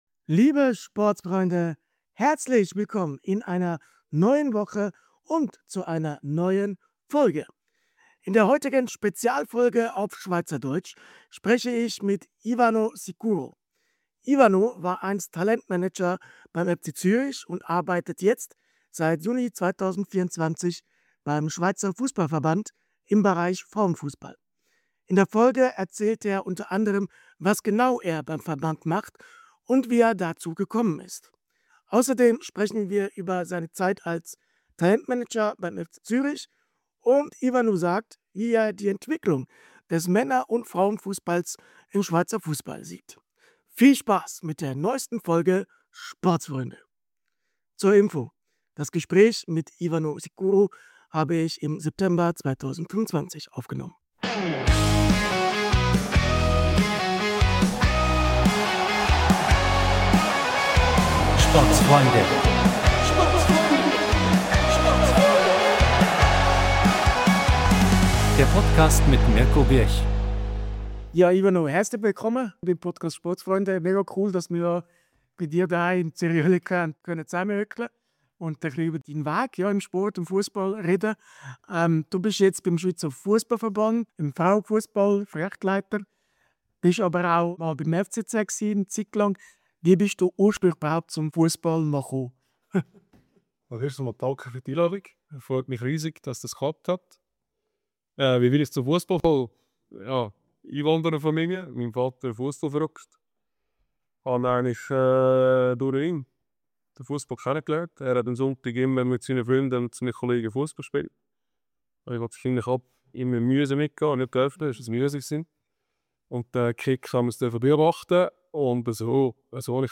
Liebe SPORTSFREUNDE, In dieser SPEZIALFOLGE auf SCHWEIZERDEUTSCH tauchen wir tief in die Welt des Schweizer Fussballs ein.